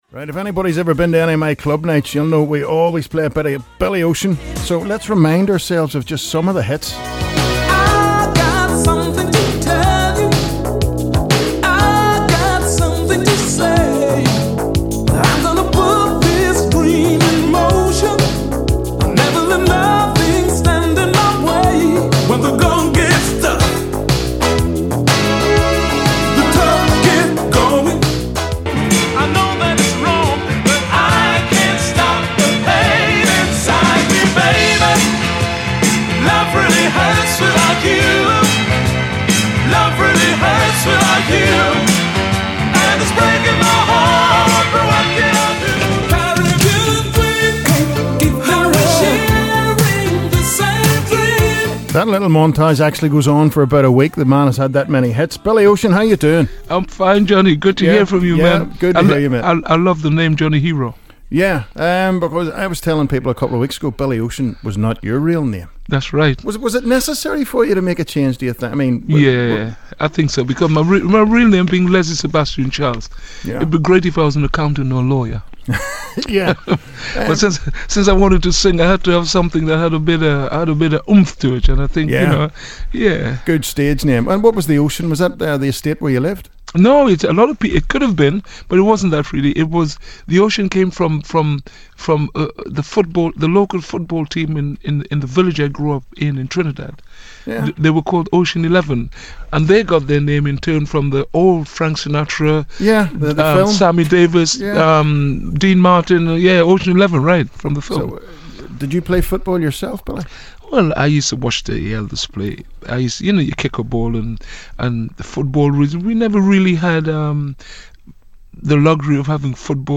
BIlY OCEAN INTERVIEW